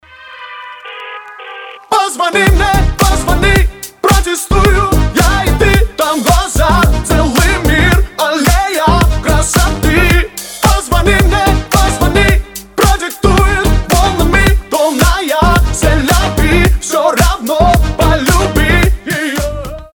• Качество: 320, Stereo
зажигательные